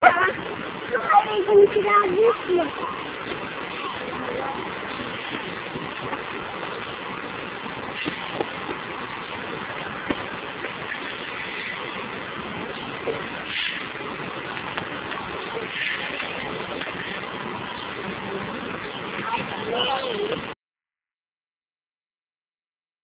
These are a series of short clips from a VHS video shot at the Erie Zoo, Erie Pennsylvania in the late 1980's The videos are in real video format.
Zebra
zebra1.ram